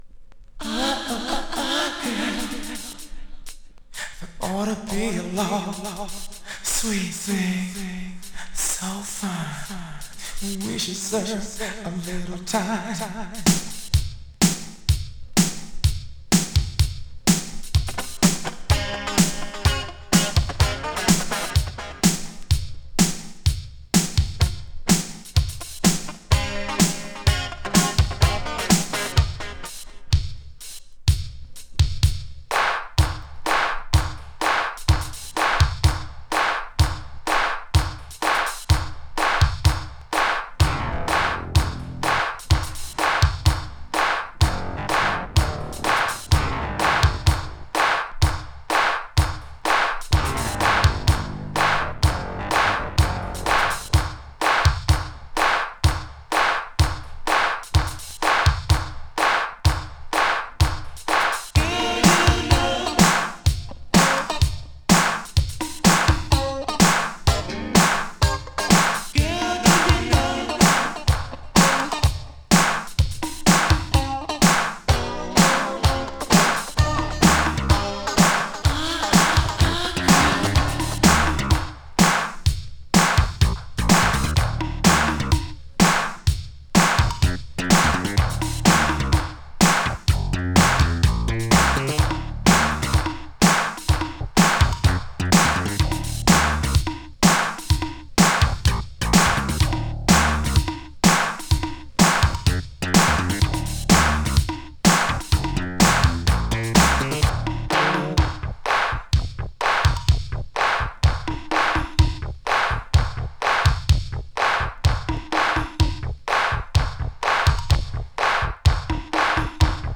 Indie Boogie from L.A.!
ファンキーなベースラインが印象的な好シンセ・ブギー！